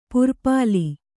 ♪ purpāli